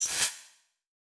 sfx_object_rotate_zap.wav